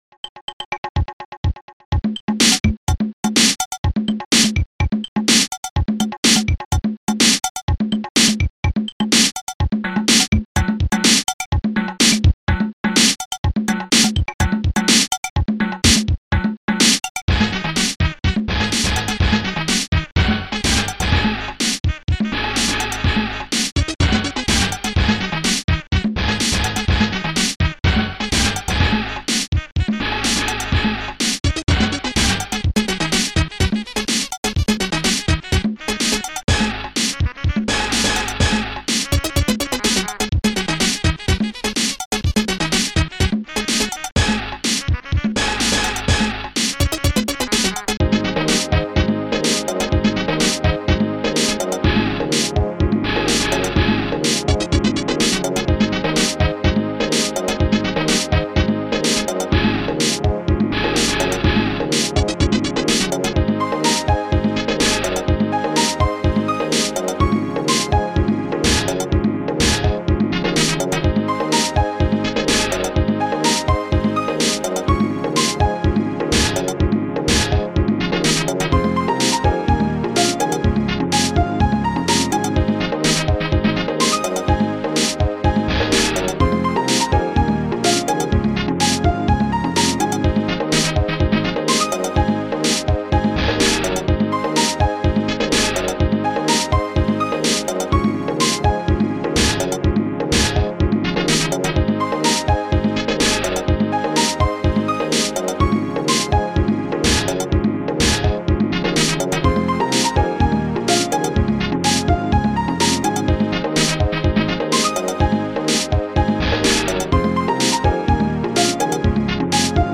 ST-01:techbassdrum
ST-01:techconga1
ST-01:techcowbell
ST-01:riksnare2
st-09:steppipsax
st-09:trainbass
ST-04:dxpianostring